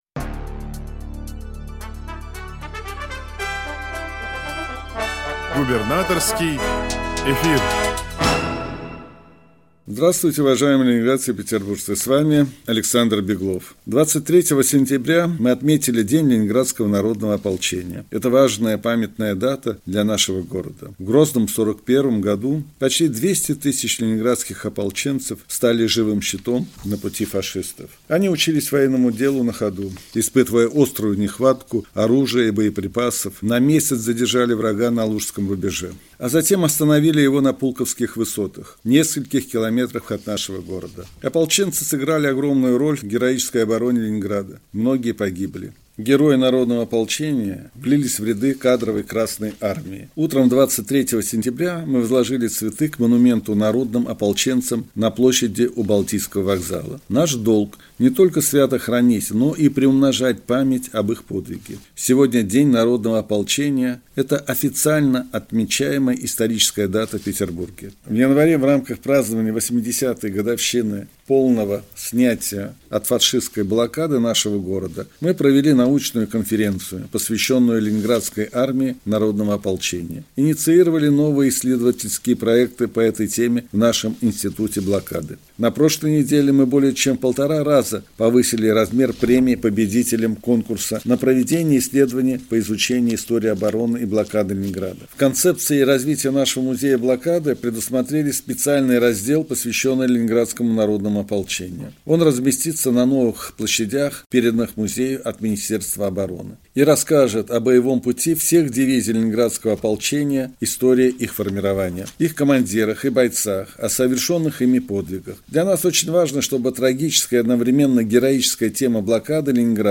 Радиообращение – 23 сентября 2024 года